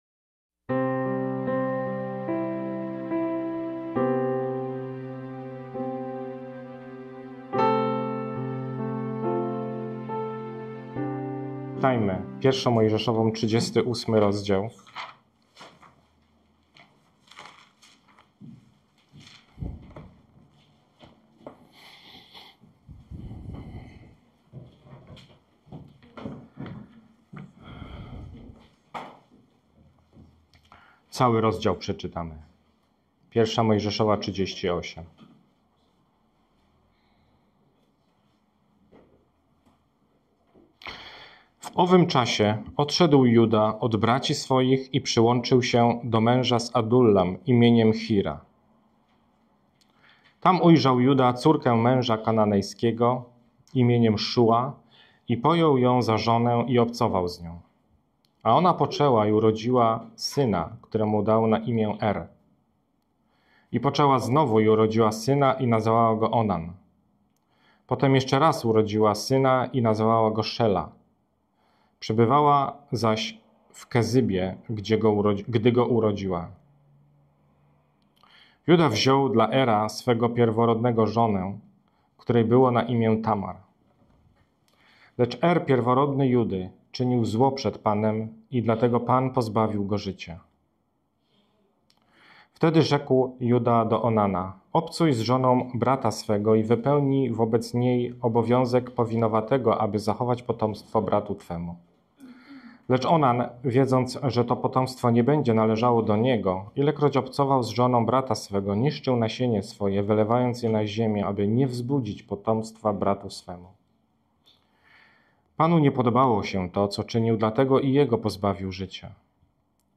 1 Księga Mojżeszowa 38 Rodzaj: Nabożeństwo niedzielne « Potrójny upadek Judy Najważniejsze